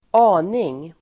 Uttal: [²'a:ning]